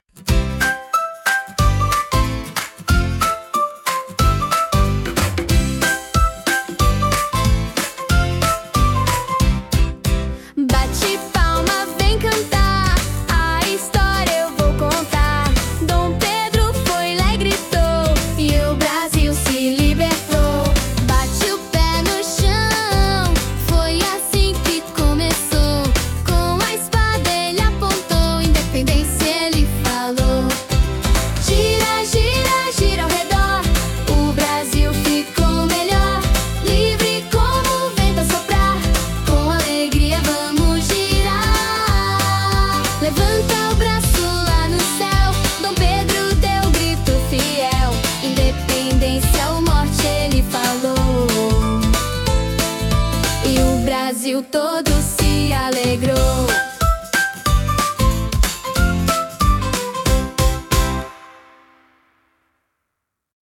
MÚSICA INFANTIL SOBRE A INDEPENDÊNCIA DO BRASIL: APRENDIZADO LÚDICO E DIVERTIDO PARA A EDUCAÇÃO INFANTIL
Palavras-chave: música infantil, independência do Brasil, planejamento semanal, atividades lúdicas, educação infantil, Dom Pedro I, BNCC, pedagogia, desenvolvimento infantil, aprendizado divertido, datas comemorativas, história do Brasil, canção educativa, atividades musicais, educação criativa, sala de aula, recursos pedagógicos, música com movimentos, ensino da história, música infantil Brasil
Letra da Música Infantil: Independência do Brasil